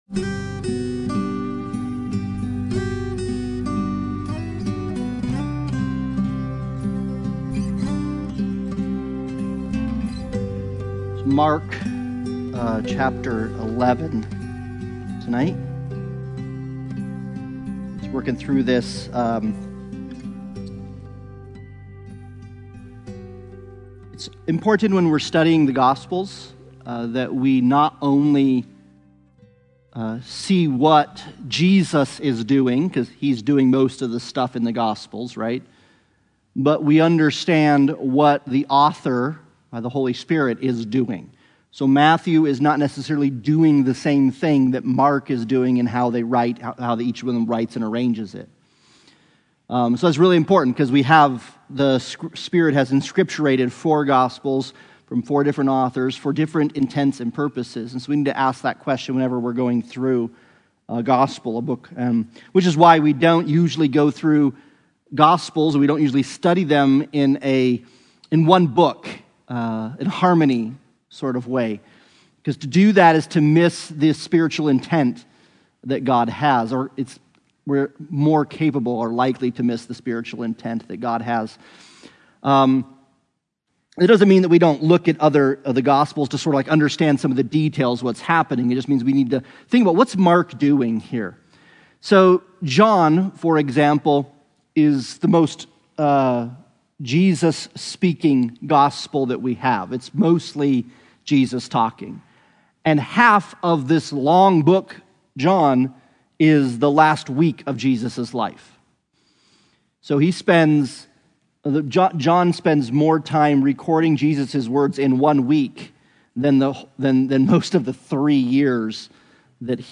Sunday Bible Study